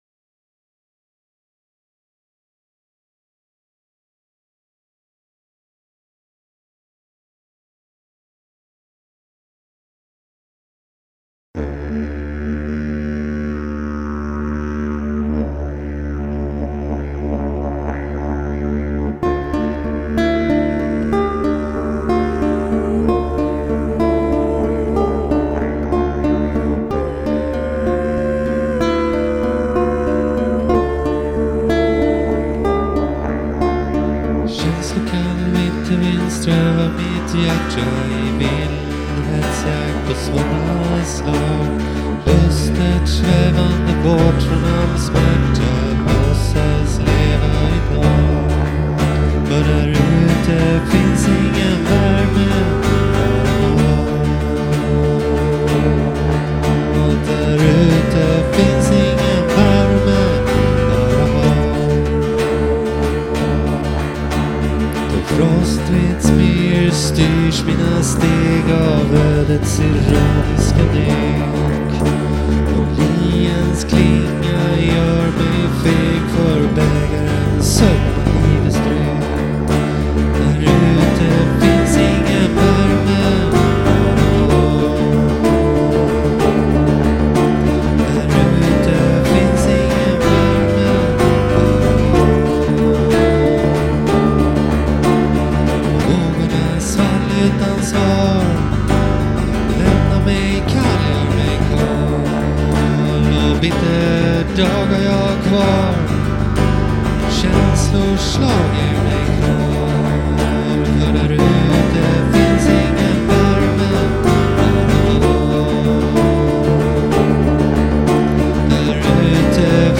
En önskvärd liten hemmastudio...
sång och inspiration